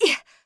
throw_v.wav